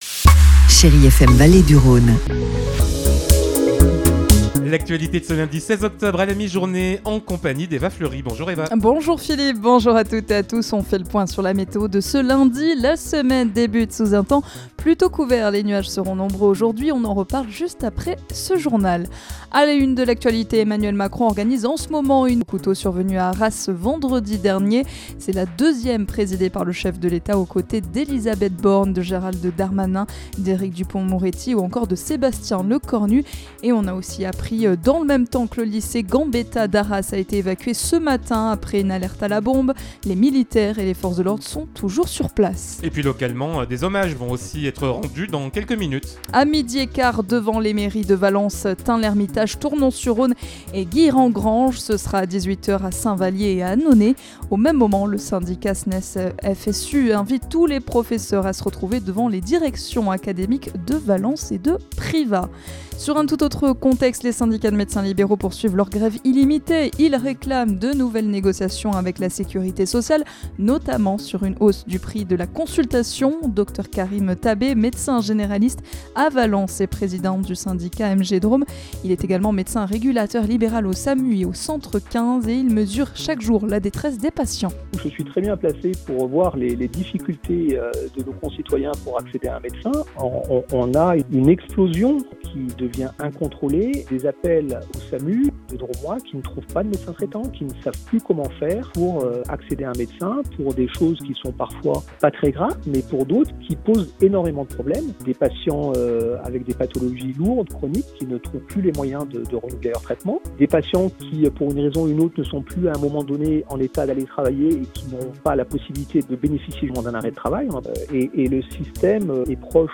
Lundi 16 octobre : Le journal de 12h